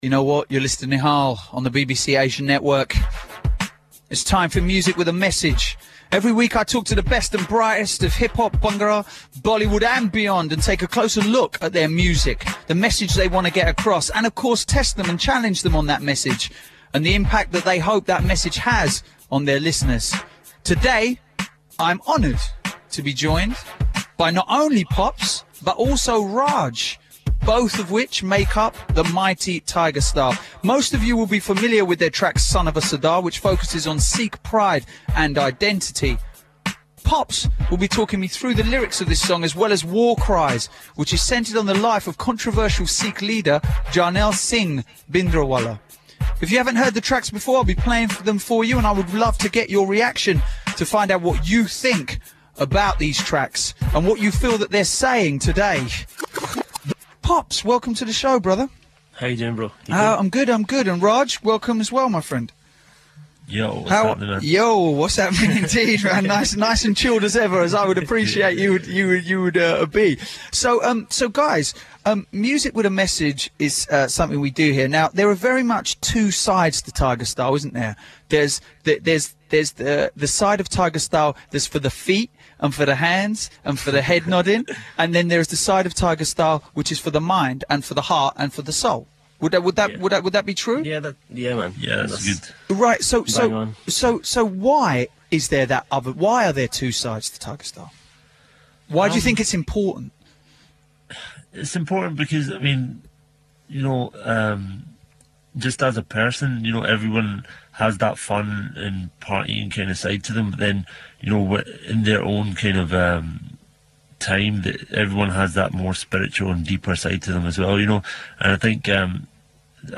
BBC Asian Network Interview With Tigerstyle BBC Asian Network, 5th June 2007 4mp3